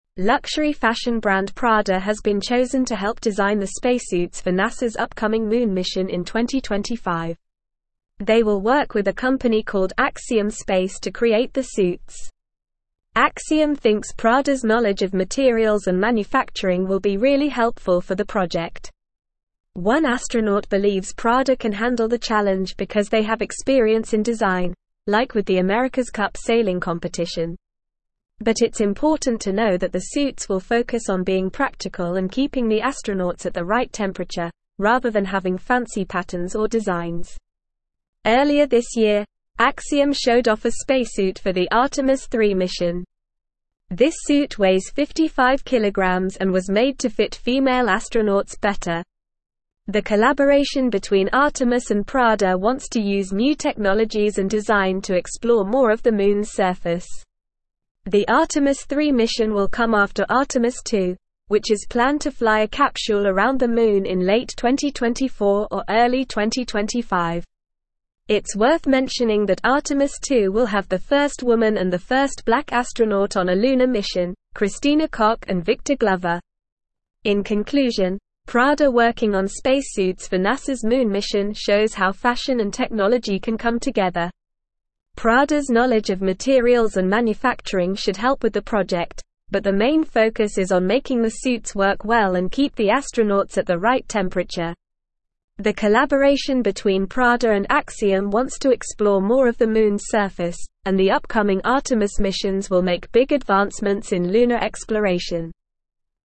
Normal
English-Newsroom-Upper-Intermediate-NORMAL-Reading-Prada-and-Axiom-Space-Collaborate-on-NASA-Moon-Mission.mp3